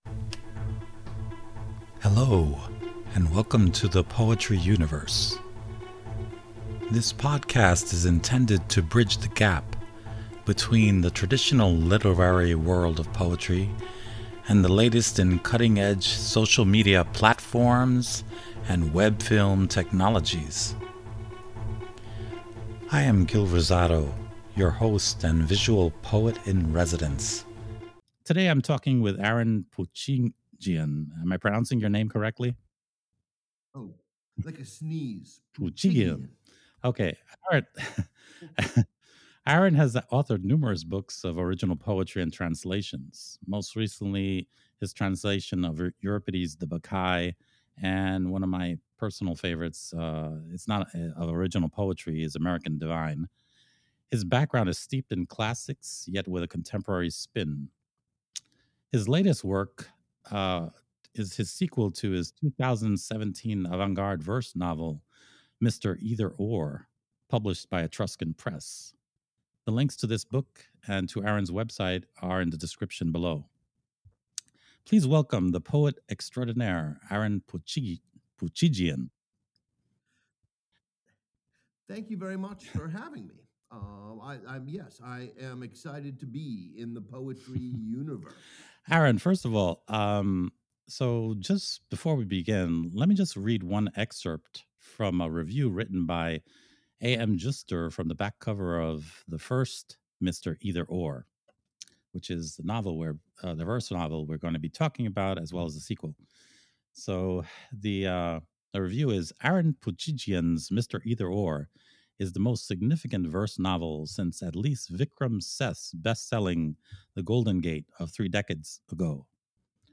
MR. EITHER/OR - AN INTERVIEW